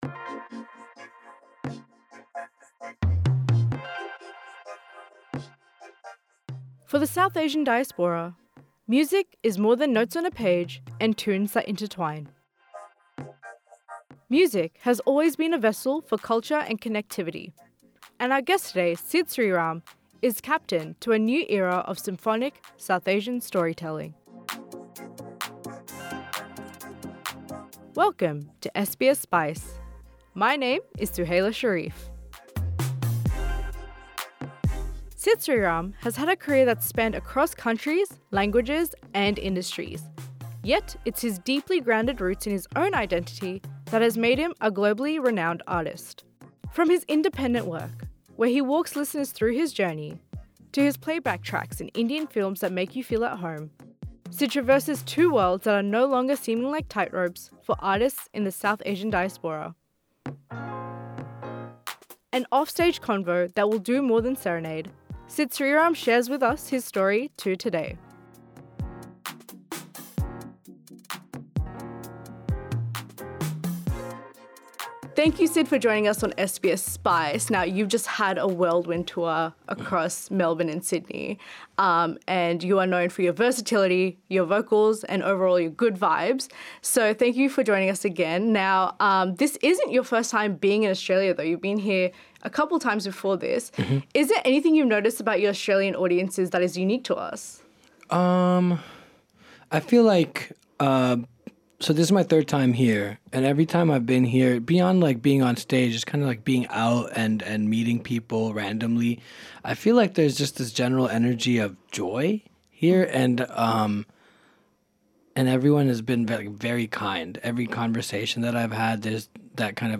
Sid Sriram joins SBS Spice in the studio.